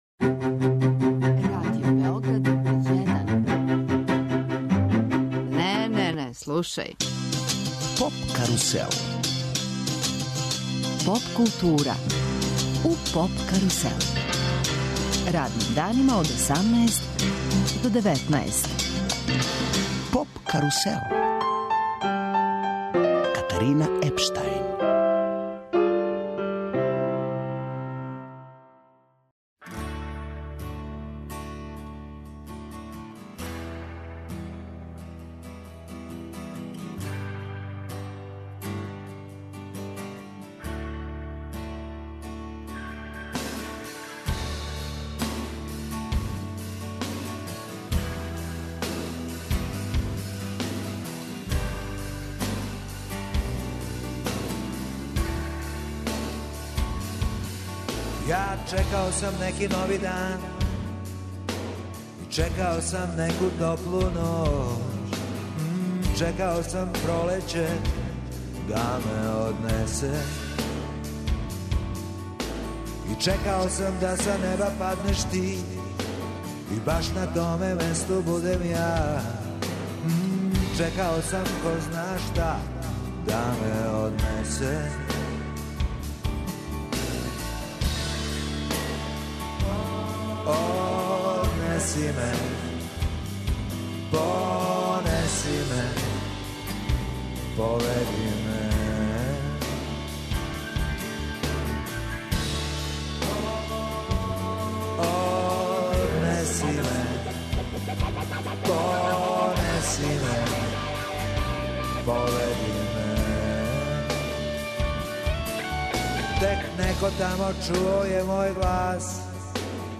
Радио специјал, посвећен Влади Дивљану. Гост емисије је Зденко Колар, један од његових најближих пријатеља и сарадника.